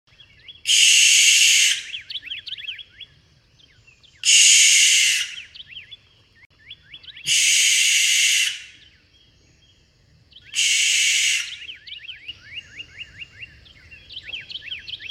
🦉 Barn Owl’call. . . Sound Effects Free Download